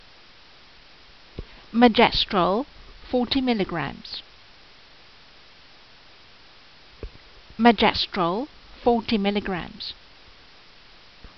Pronunciation[edit]
Megestrol_40mg.mp3